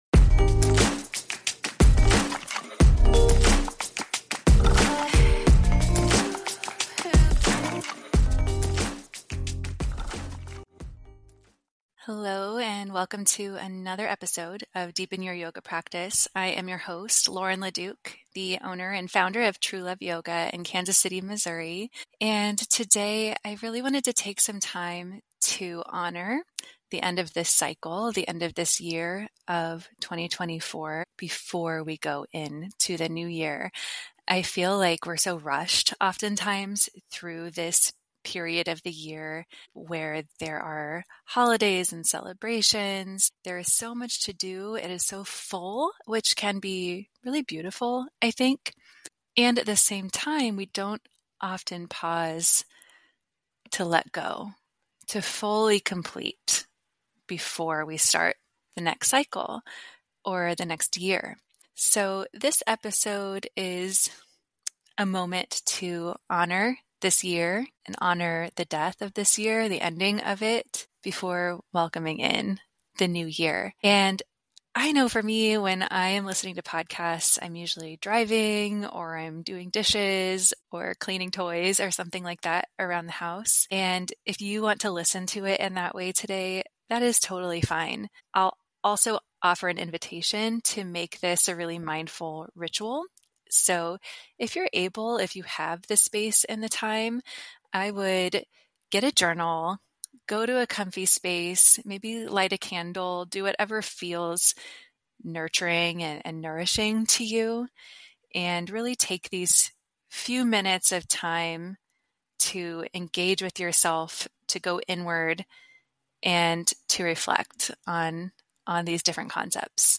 The conversation delves into the definitions of Qigong, the concept of Qi as life force energy, and the sig…